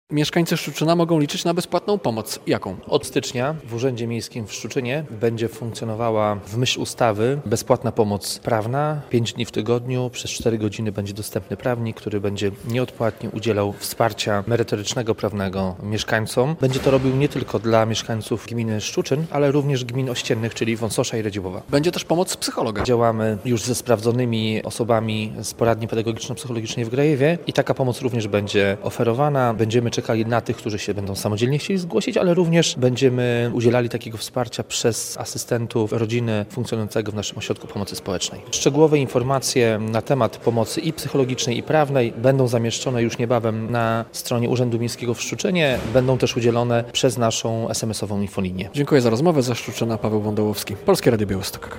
Bezpłatne wsparcie prawne i psychologiczne otrzymają mieszkańcy Szczuczyna. Od początku roku konsultacje będą dostępne w Urzędzie Miasta mówi burmistrz Artur Kuczyński